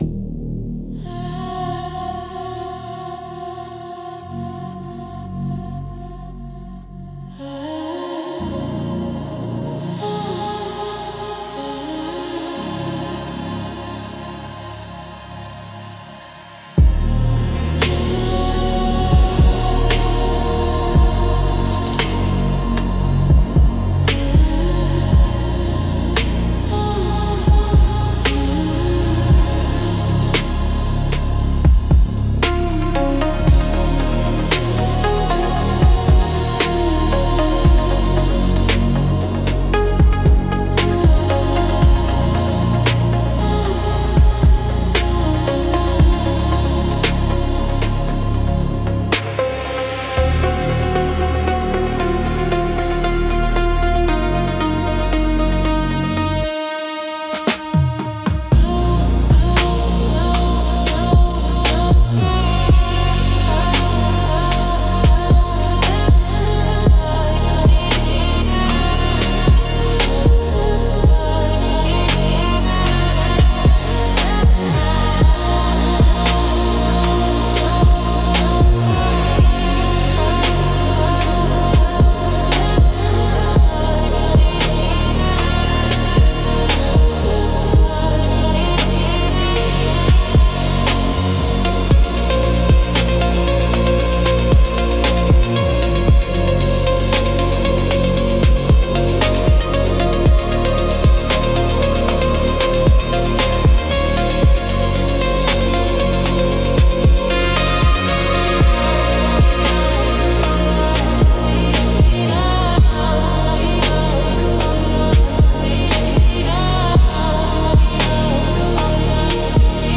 Instrumental - Aurora in the Subfrequency 3.48